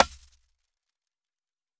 Djembe AfroShop 9.wav